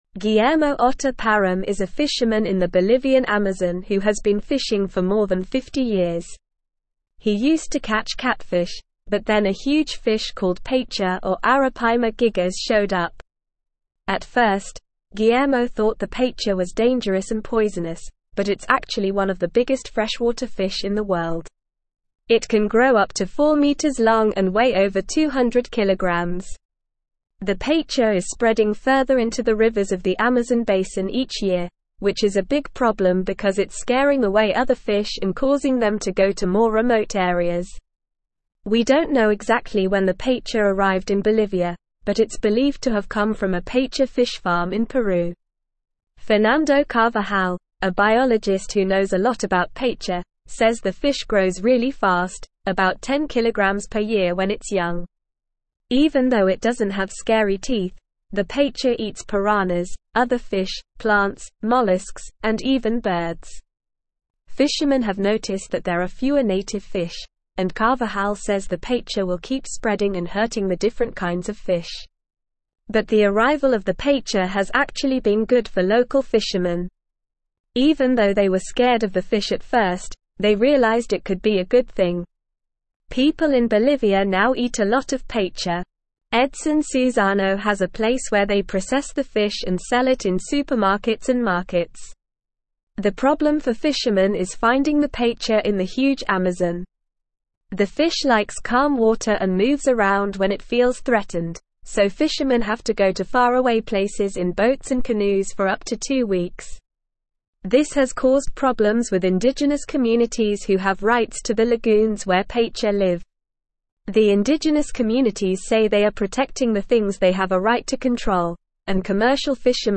Normal
English-Newsroom-Upper-Intermediate-NORMAL-Reading-Invasive-paiche-threatens-Bolivian-Amazon-fish-stocks.mp3